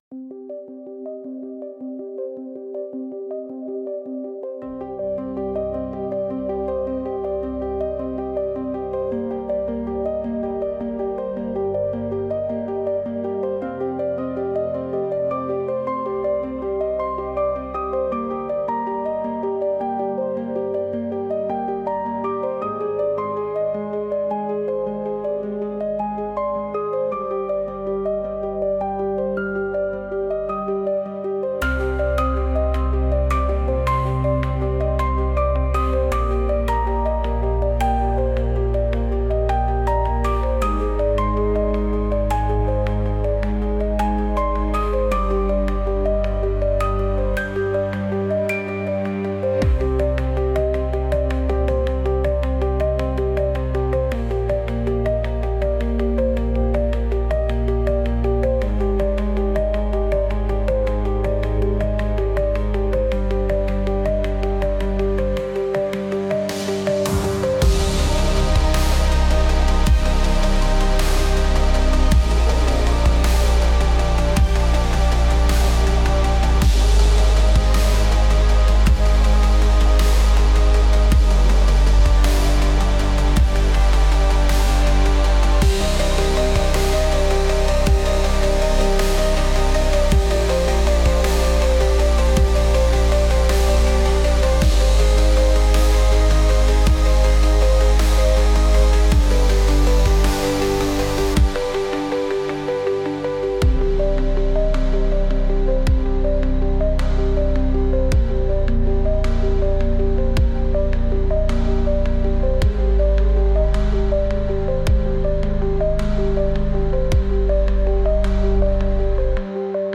Instrumental -Lost in the Night 4.00